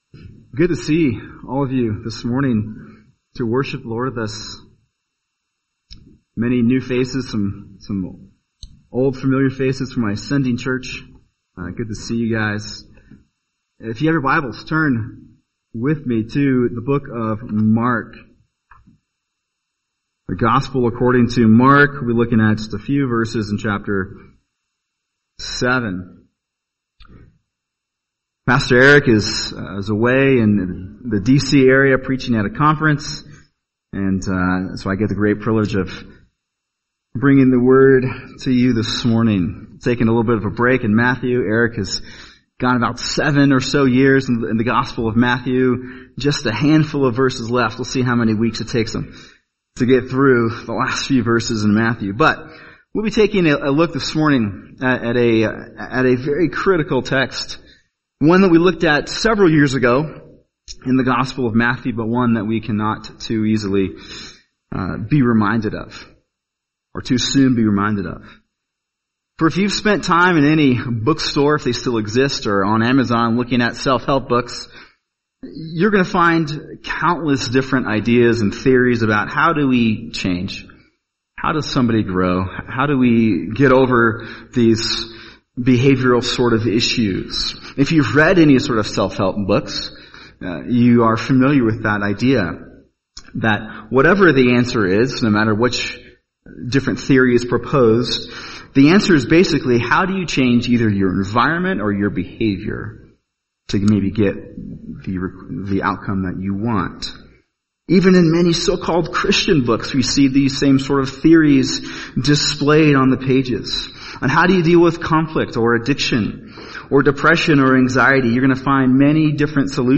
[sermon] Mark 7:14-23 Heart Blindness | Cornerstone Church - Jackson Hole